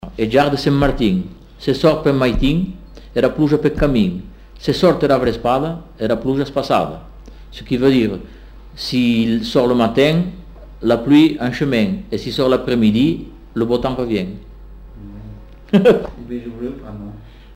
Aire culturelle : Comminges
Lieu : Bagnères-de-Luchon
Effectif : 1
Type de voix : voix d'homme
Production du son : récité
Classification : proverbe-dicton